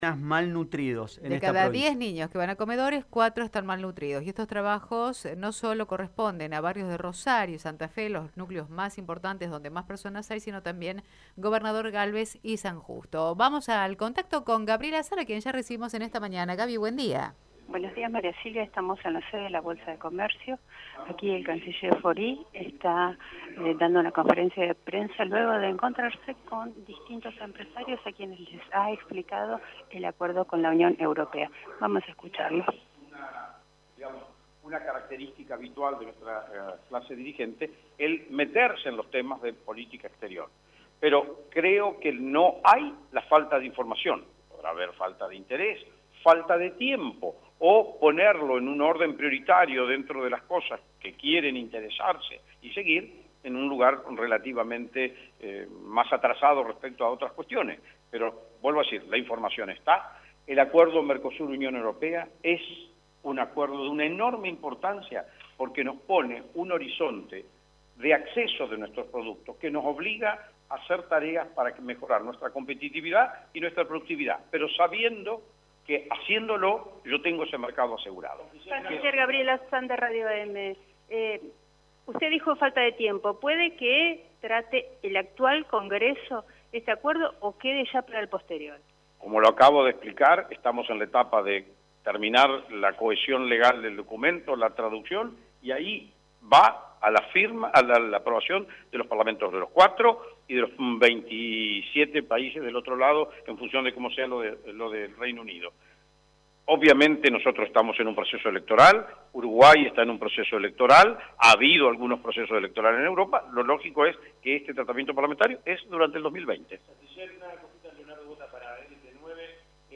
El canciller argentino Jorge Faurie se hizo presente este martes en la Bolsa de Comercio de Santa Fe. Allí brindó detalles del acuerdo Mercosur-Unión Europea (UE) firmado este año, así como el futuro de la Hidrovía Paraná-Paraguay, cuyo contrato deberá ser renegociado nuevamente.